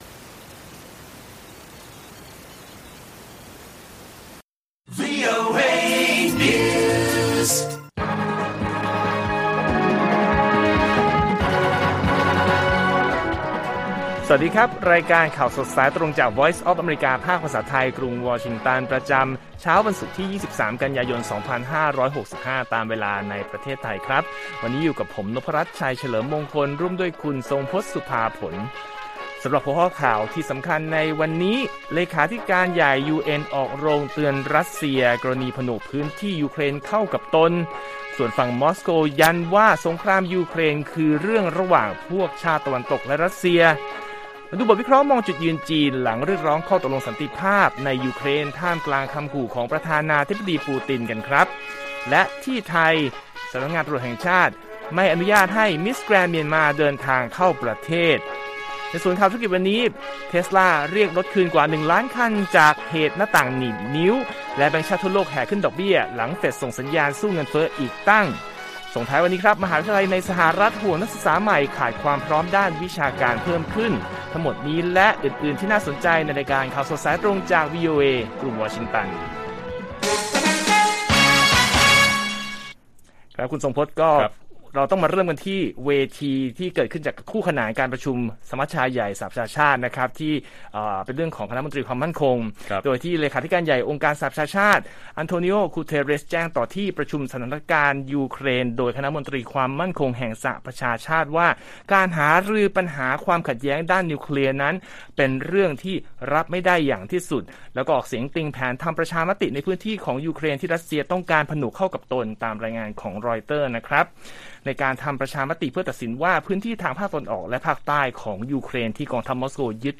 ข่าวสดสายตรงจากวีโอเอไทย 6:30 – 7:00 น. วันที่ 23 ก.ย. 65